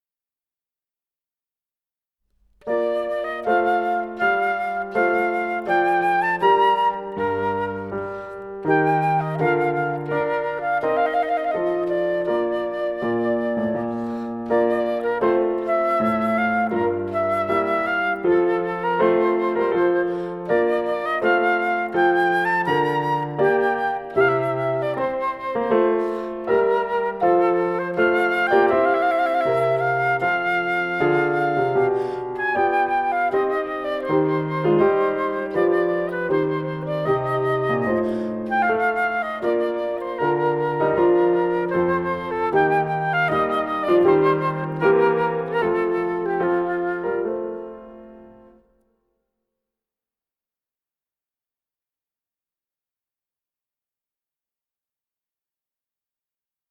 スタジオ・ヴァージョン(Fl. Pf.)